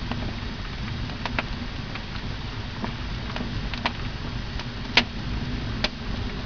smallblaze.wav